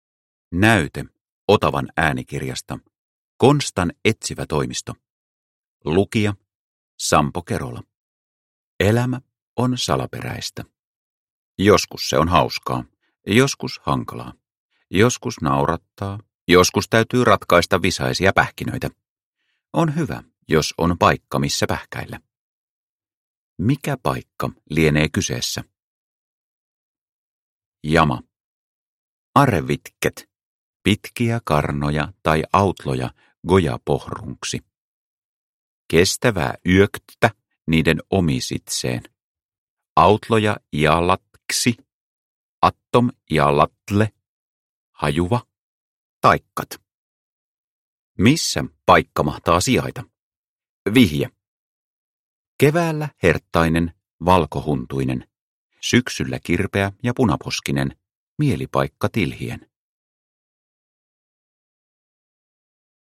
Konstan etsivätoimisto – Ljudbok – Laddas ner